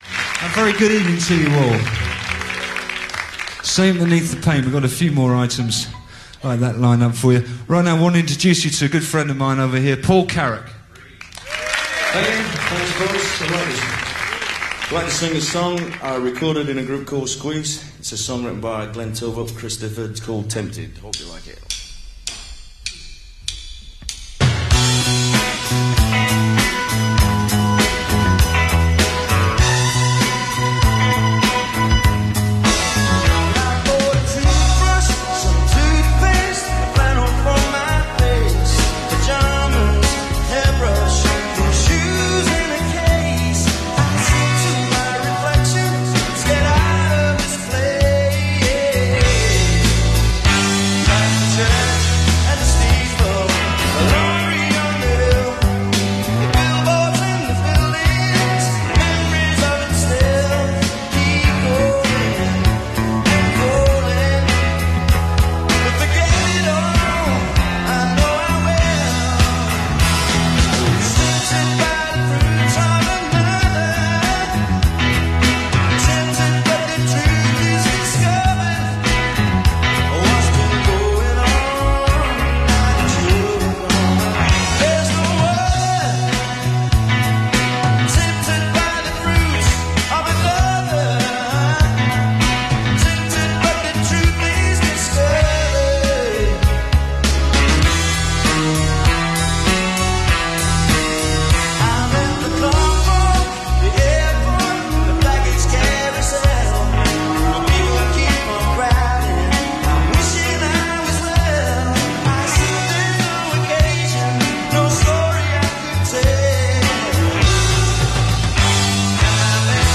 Live at The Paris Theatre, London